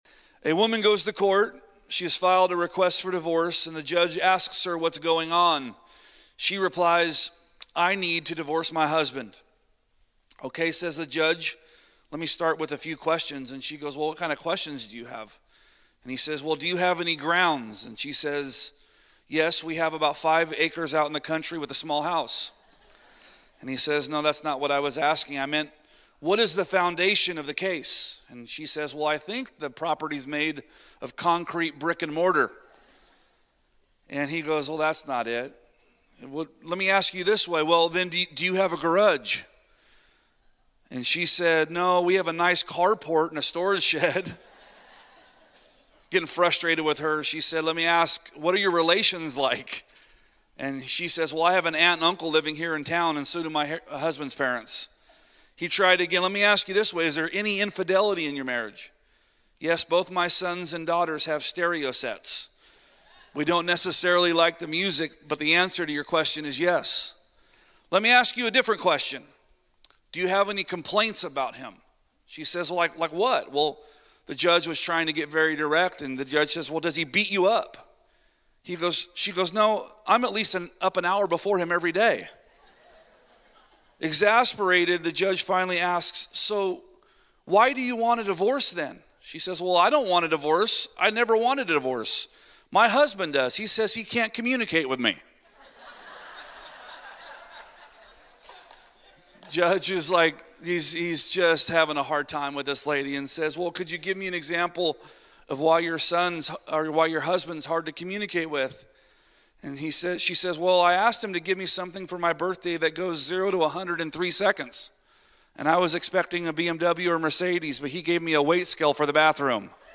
A message from the series "The God of Both." Spending time WITH God is better than spending time ALONGSIDE Him. both are valuable but only one should be a prior